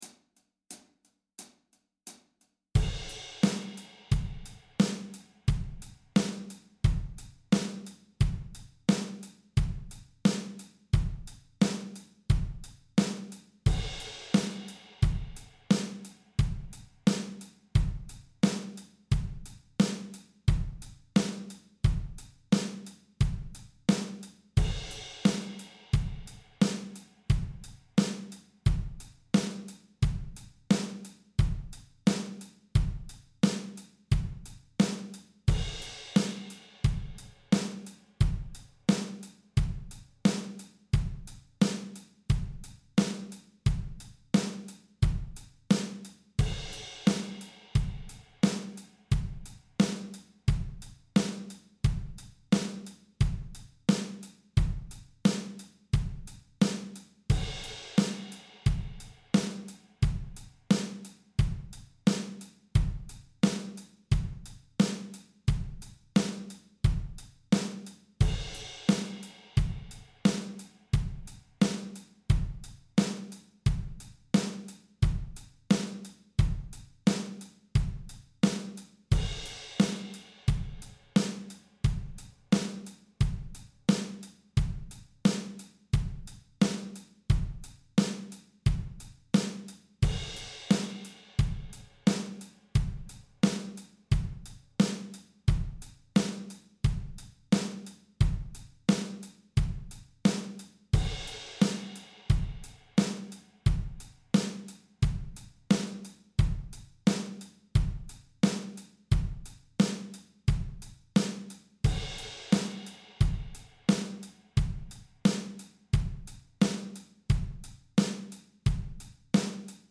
Drum Tracks for Extra Practice
With that in mind, I put together some basic bass drum & snare combos that evoke the AC/DC sound.
Mid-tempo (88bpm) - download, or press the play button below to stream:
584-drums-88bpm.mp3